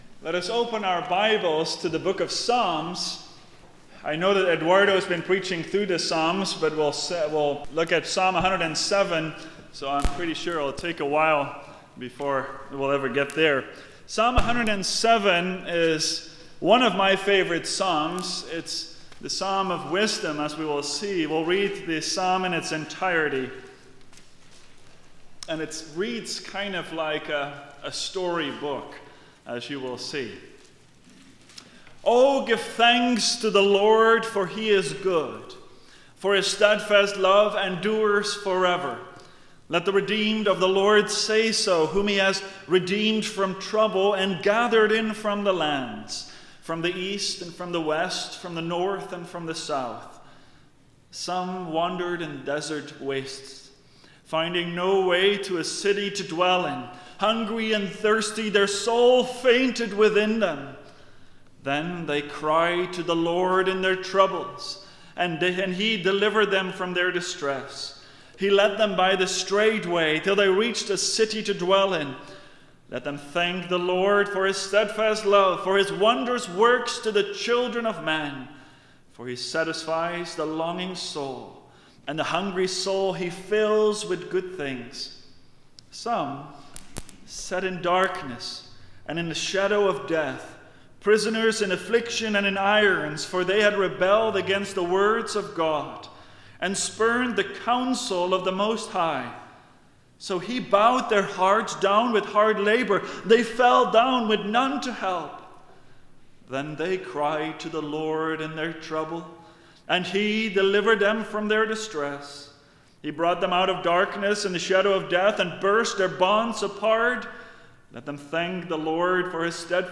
Thanksgiving Sermons